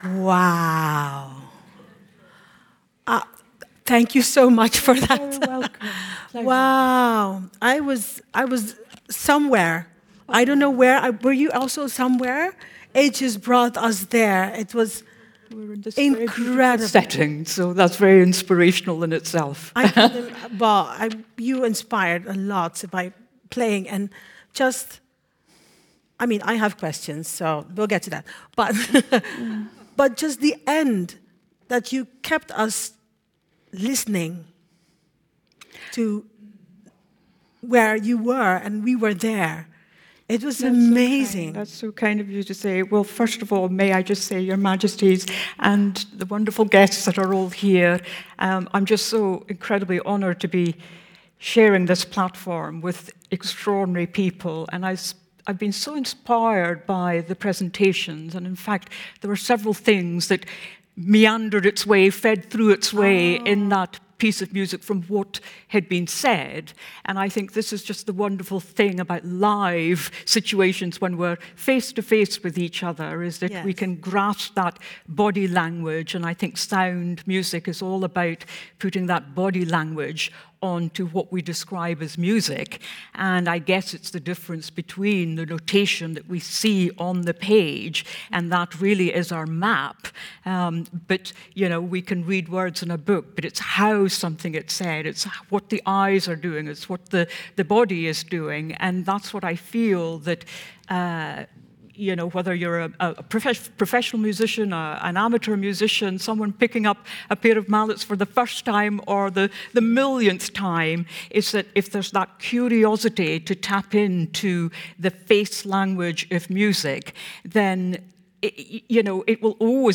05_Interview_Tania_Kross_with_Evelyn_Glennie.mp3